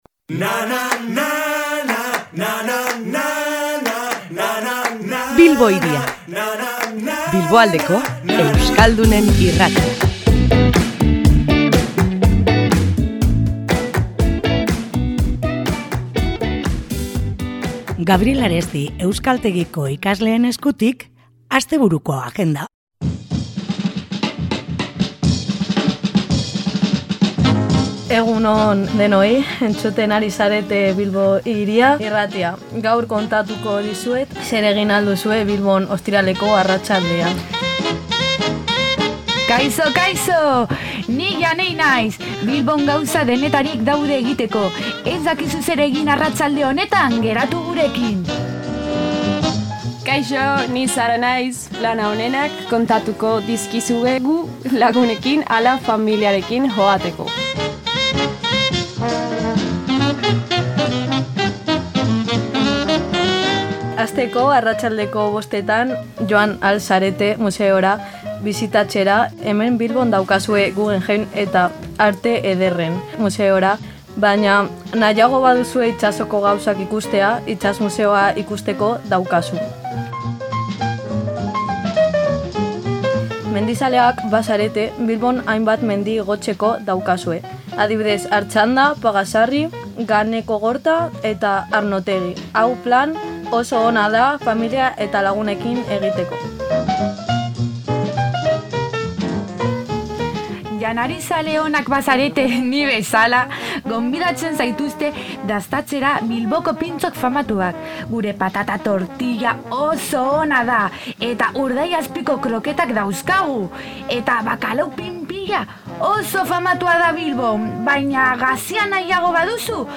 Gabriel Aresti euskaltegiko ikasleak izan ditugu gurean, eta astebururako plan interesgarriak ekarri dizkigute Bilbo Hiria irratira. Gaurko ikasleak animazioz beterik etorri dira! Museoak bisitatzea gomendatu digute, eta, era berean, mendira irteera egiteko proposamena ere egin digute.